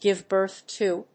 アクセントgìve bírth to…